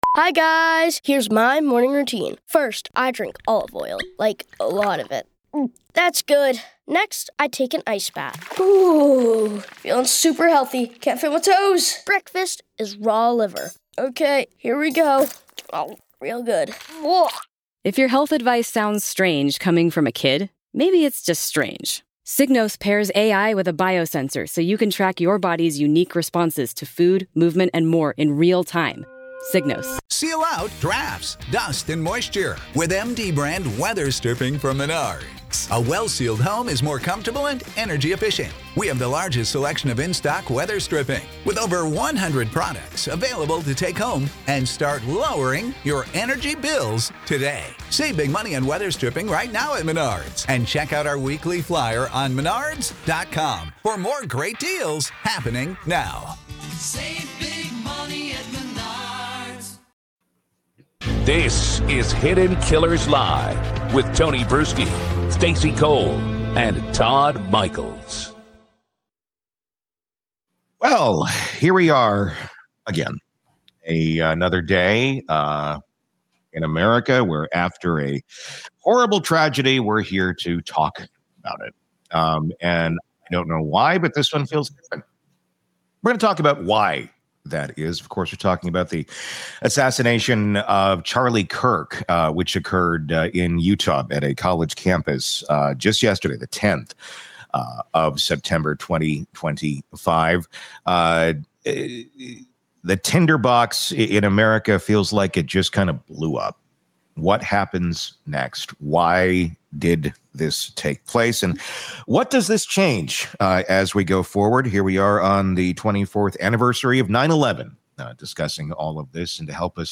Segment 3: Conspiracy theories, hopelessness, and collective trauma after the assassination, capped by FBI press conference updates.